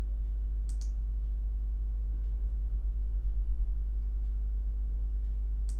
Problem with low signal on mic
This is with gain at 3/4, just so it can be audible.
Attachments noise.mp3 noise.mp3 83.6 KB · Views: 121